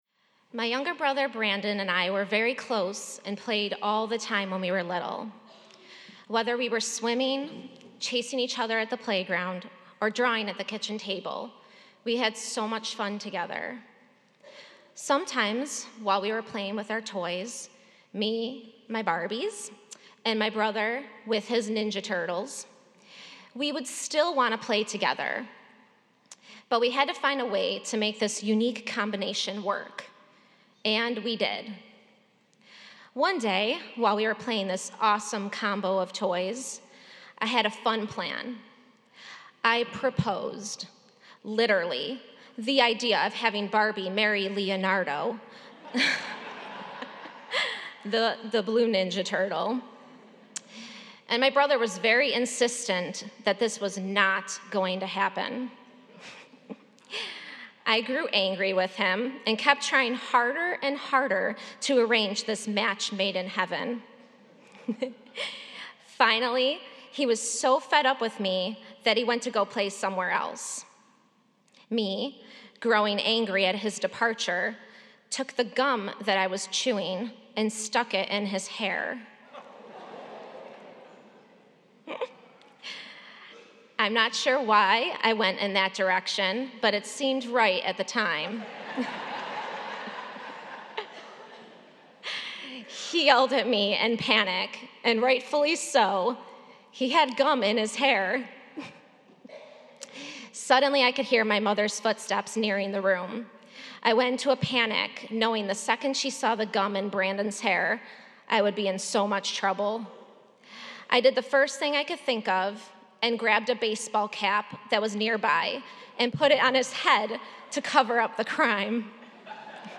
preaches that with love and trust something we think is small and insignificant can be grown into something great and beautiful.